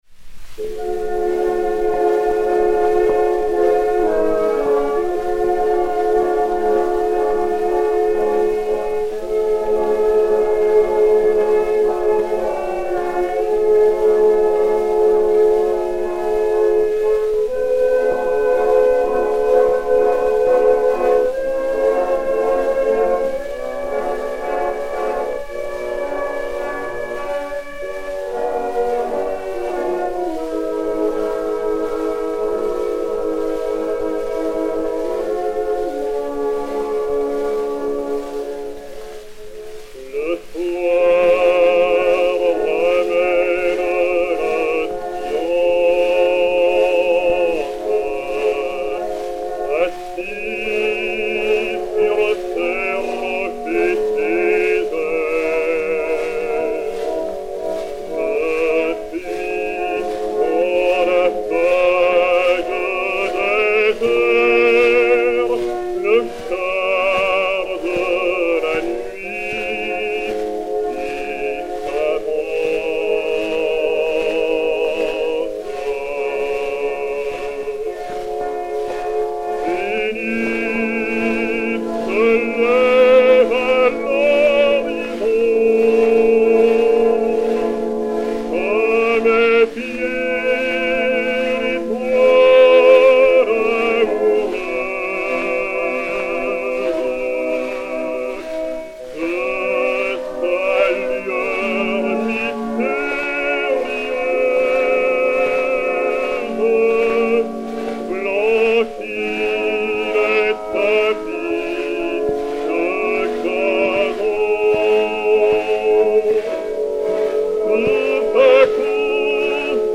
Orchestre
Zonophone X 82.571, mat. 5825o, enr. à Paris en 1906